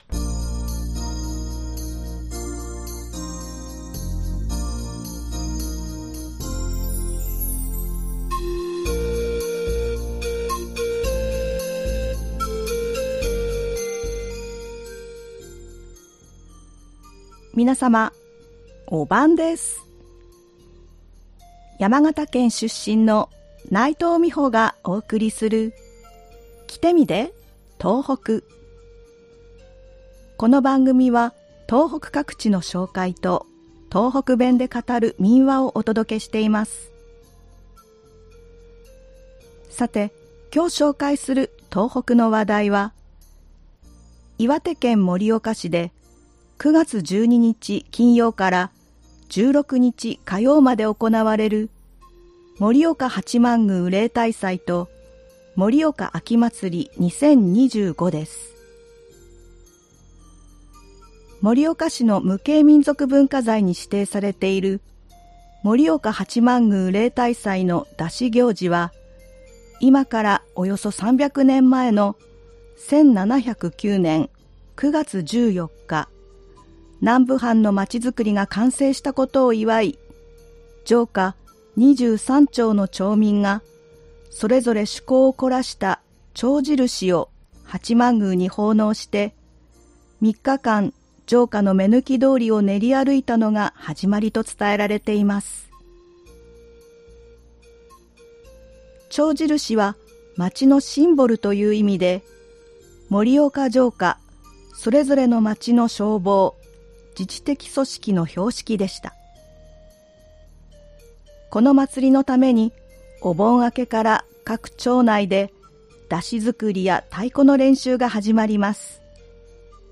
この番組は東北各地の紹介と、東北弁で語る民話をお届けしています。
ではここから、東北弁で語る民話をお送りします。今回は岩手県で語られていた民話「ほととぎすとかっこうどり」です。